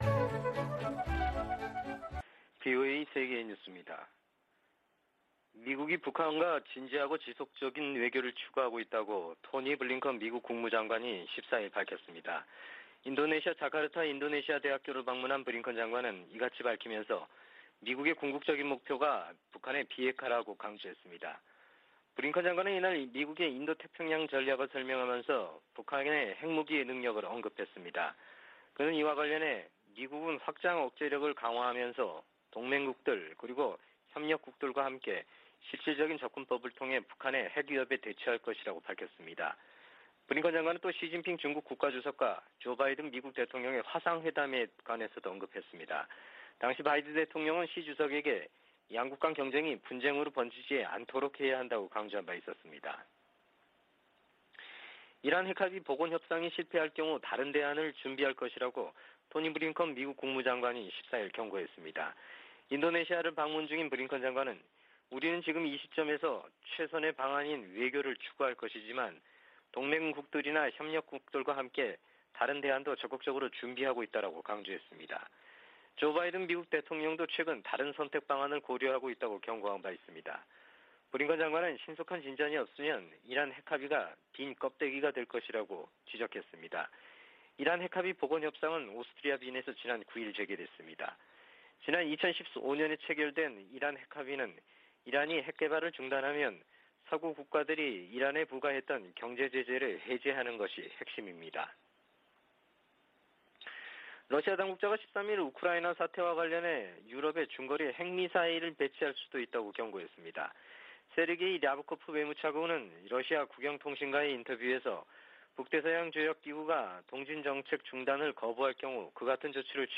VOA 한국어 아침 뉴스 프로그램 '워싱턴 뉴스 광장' 2021년 12월 15일 방송입니다. 미 국무부는 베이징 동계올림픽 외교적 보이콧에 관해 ‘한국 스스로 결정할 일’이라고 밝혔습니다. 미 국방부는 한국군 전시작전통제권 전환을 위한 완전운용능력(FOC) 평가를 내년 여름에 실시하는 계획을 재확인했습니다. 미 재무부가 북한 내 인권 유린에 연루된 개인과 기관을 제재한 효과가 제한적일 것이라고 전문가들은 평가했습니다.